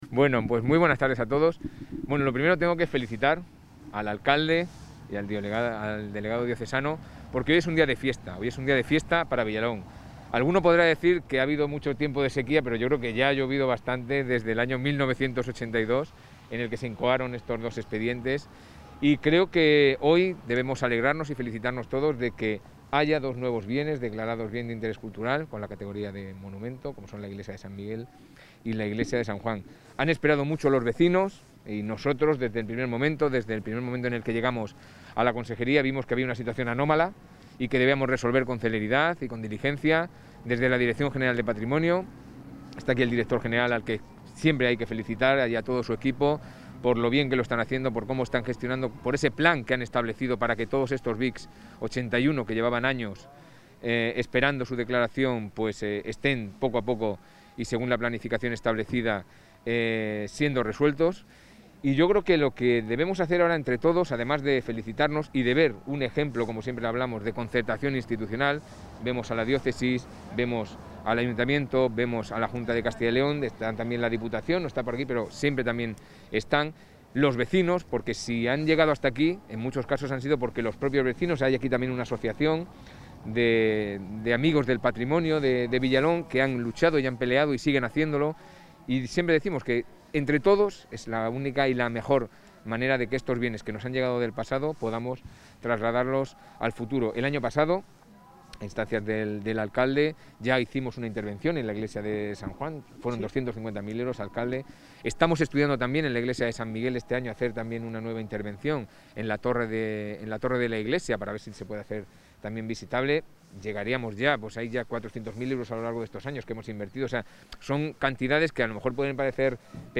El consejero de Cultura y Turismo, Javier Ortega, ha visitado hoy la localidad vallisoletana de Villalón de Campos, junto con el alcalde,...
Intervención del consejero de Cultura y Turismo.